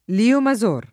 vai all'elenco alfabetico delle voci ingrandisci il carattere 100% rimpicciolisci il carattere stampa invia tramite posta elettronica codividi su Facebook Lio Mazor [ l & o ma zz1 r ; ven. l & o ma @1 r ] top. stor.